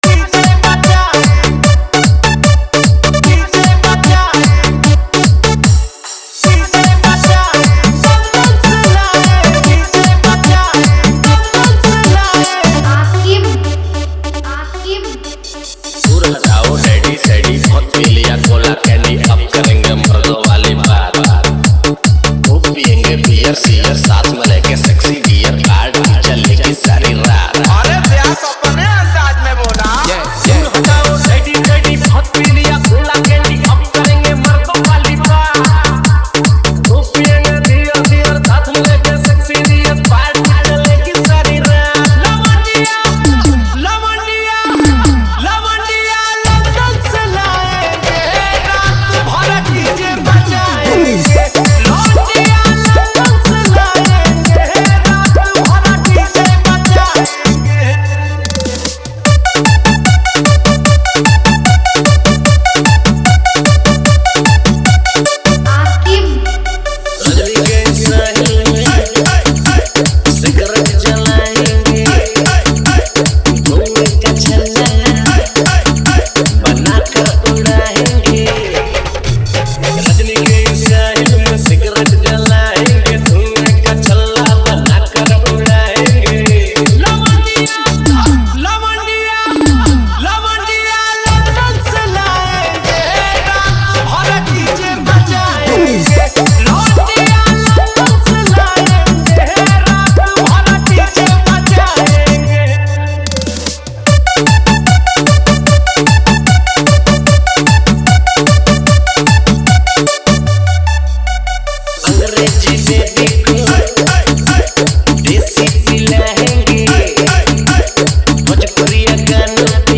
Category : Bhojpuri Dj Remix Jhanjhan Bass